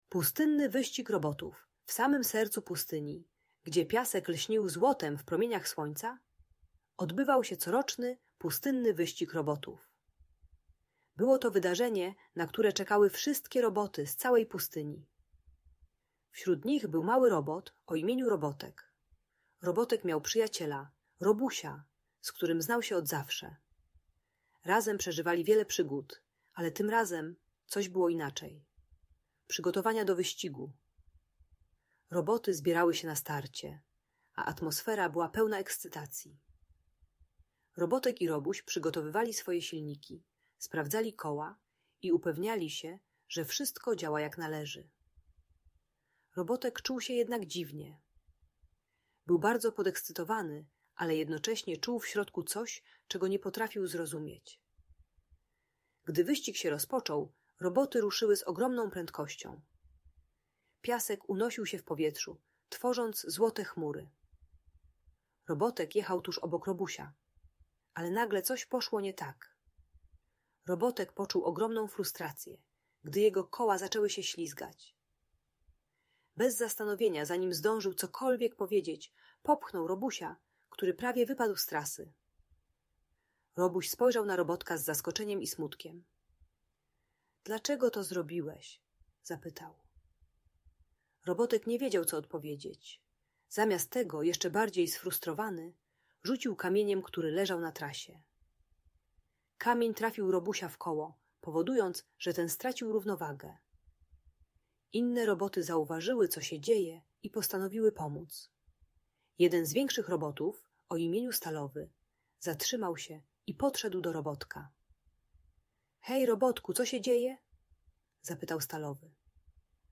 Pustynny Wyścig Robotów - Inspirująca historia o przyjaźni - Audiobajka dla dzieci